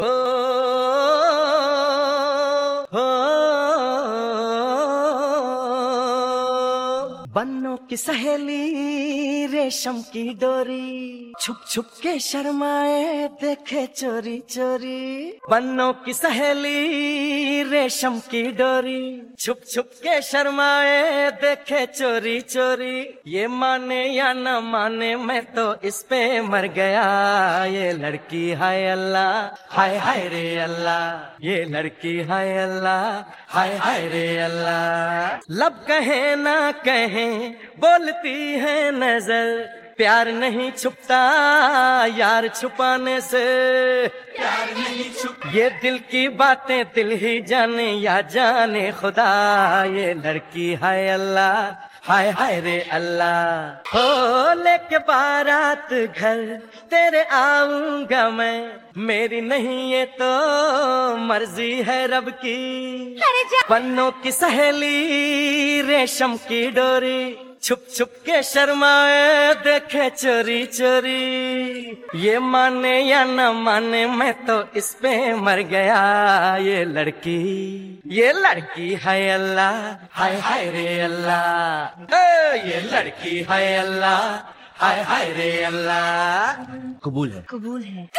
Bollywood Song